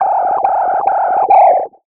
Filtered Feedback 10.wav